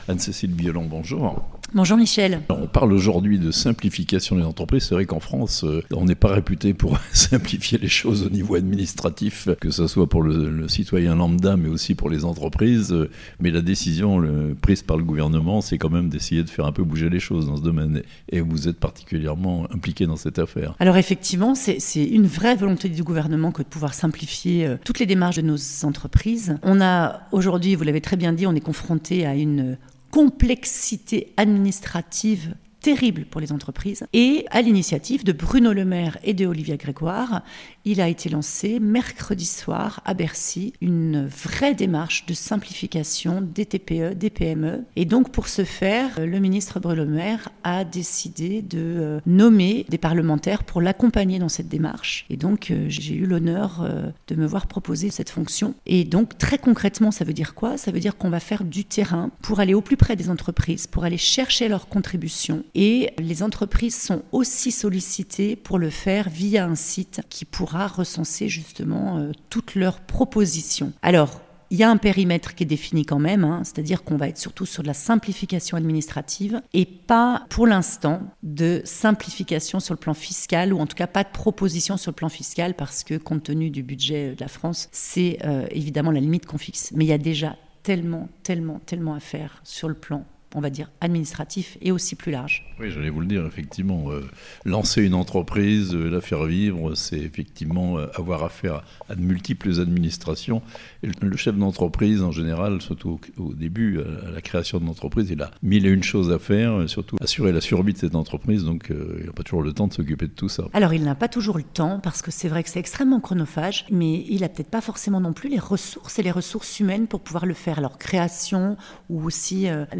Simplifier la vie des entreprises, un travail auquel va s'atteler, avec d'autres parlementaires, la Députée Anne-Cécile Violland (interview)